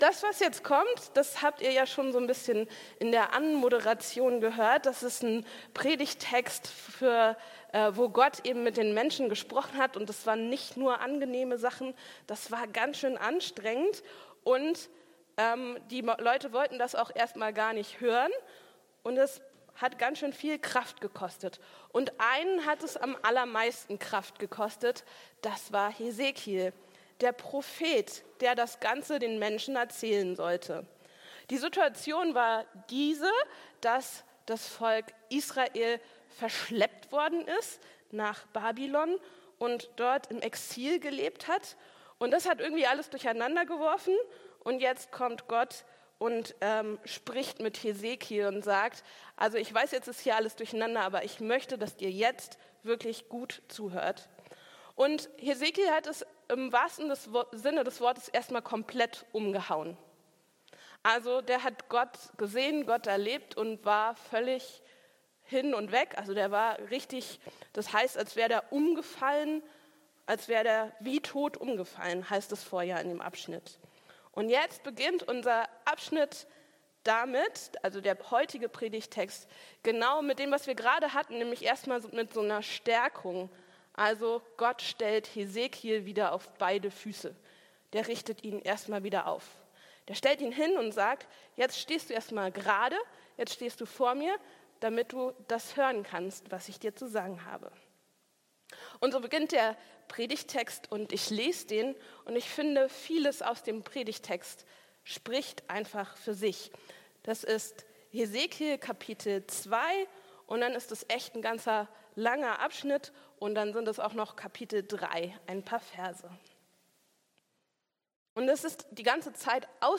Kritik ~ Christuskirche Uetersen Predigt-Podcast Podcast